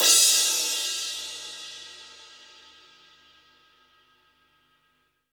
Index of /90_sSampleCDs/Roland L-CD701/CYM_Crashes 1/CYM_Crash menu